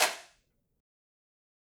MB Perc (5).wav